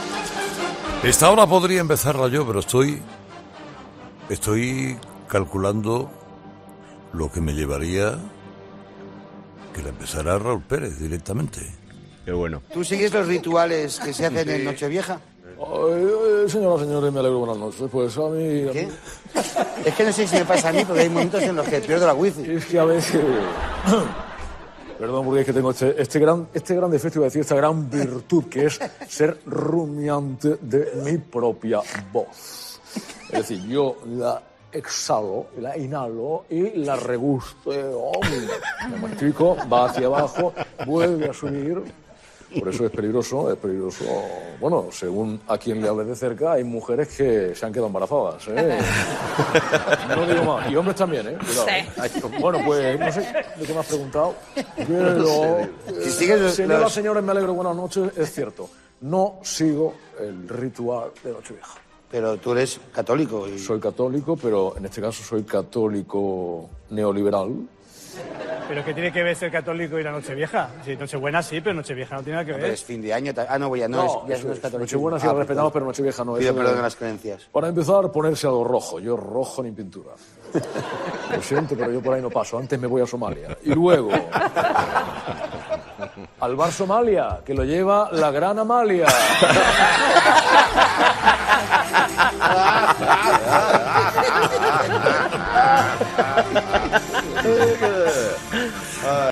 El equipo de 'Herrera en COPE' no ha podido contener las carcajadas al escuchar la imitación que ha hecho el cómico del director y presentador del programa
Carlos Herrera reacciona a la imitación de Raúl Pérez